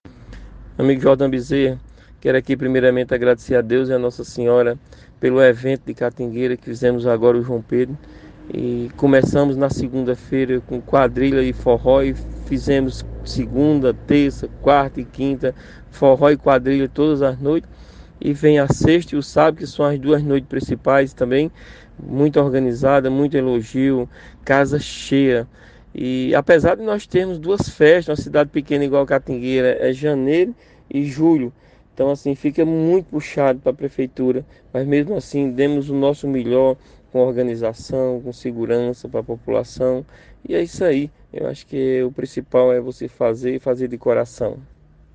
O prefeito se mostrou orgulhoso de sua equipe que fez uma grande festa.